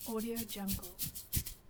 دانلود افکت صدای خاراندن سر
افکت صدای خاراندن سر Scratching Head یک گزینه عالی برای هر پروژه ای است که به صداهای انسانی و جنبه های دیگر مانند خاراندن، خارش سر و خاراندن پوست نیاز دارد.
Sample rate 16-Bit Stereo, 44.1 kHz
Looped No